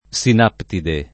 [ S in # ptide ]